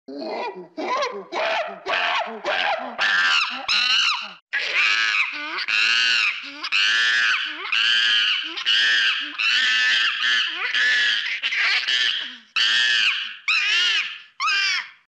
На этой странице собраны звуки макак – от игривого щебета до громких предупредительных сигналов.
Звук разъяренной макаки